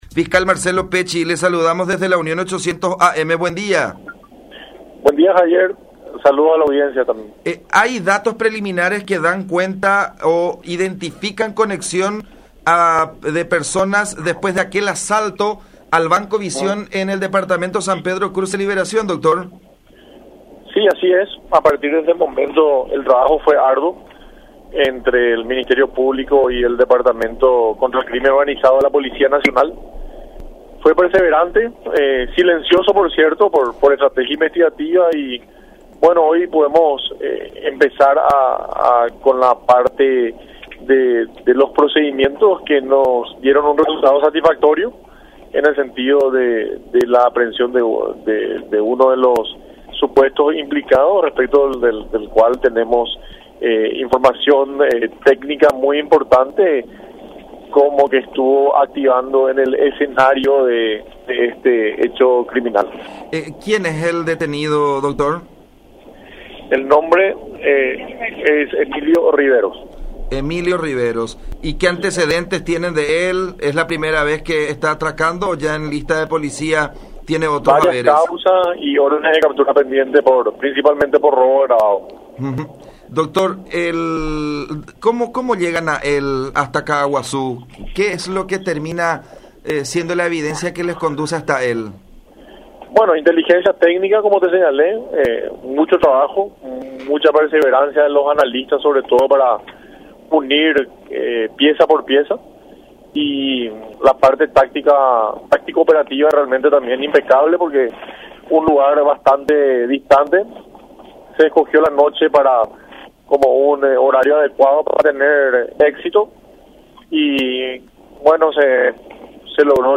detalló el fiscal interviniente, Marcelo Pecci, en contacto con La Unión.
02-Dr.-Marcelo-Pecci-Agente-Fiscal-sobre-allanamientos-en-Caaguazú.mp3